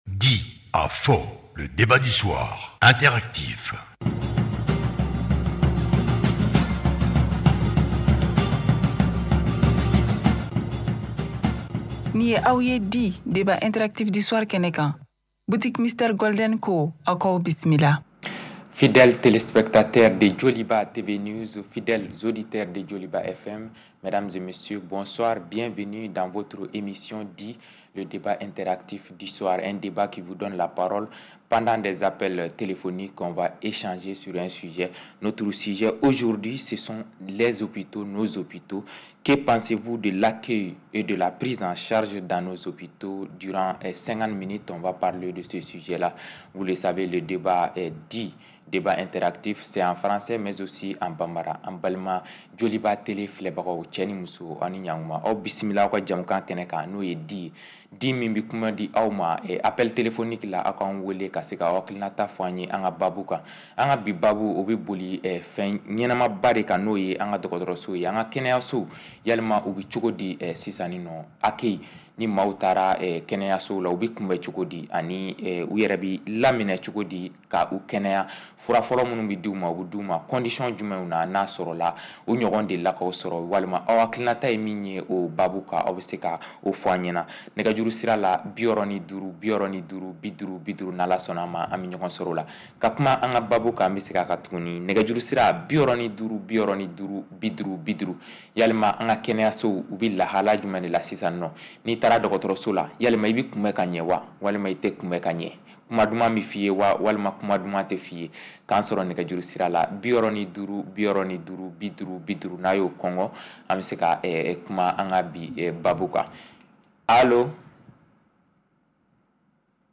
Appelle-nous et donne ton point de vue sur une question d’actualité (politique, économique, culturelle, religieuse, etc.). Pas de sujets tabous : arguments, contre arguments !